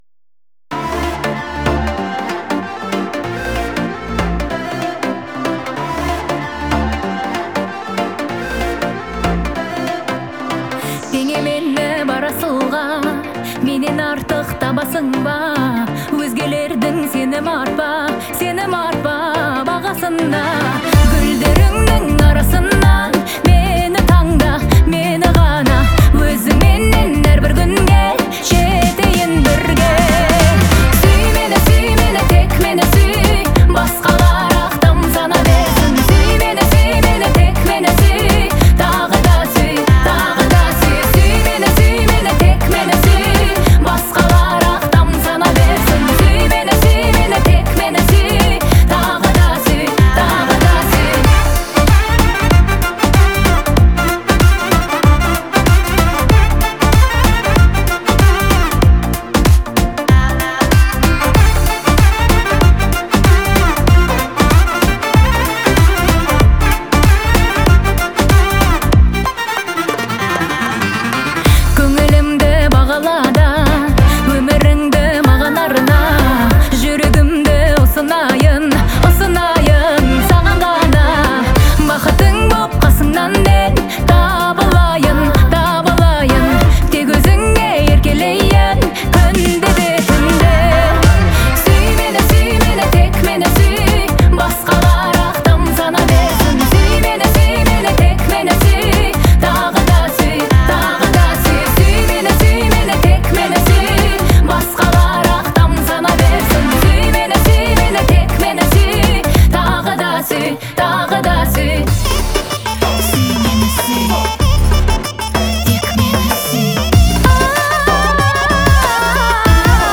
это трогательная казахская песня в жанре поп